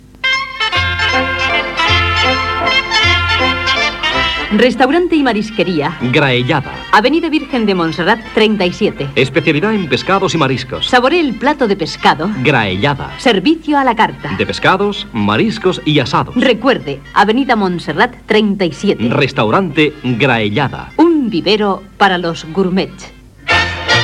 Primer anunci que es va enregistrar als estudis de la Via Augusta de Radio Juventud RJ2.